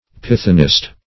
Pythonist \Pyth"o*nist\, n. A conjurer; a diviner.
pythonist.mp3